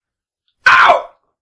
僵尸电话 " 高位僵尸电话
描述：嗨摇晃僵尸电话
Tag: 怪物 怪物 电话 僵尸 尖叫 科幻 低吼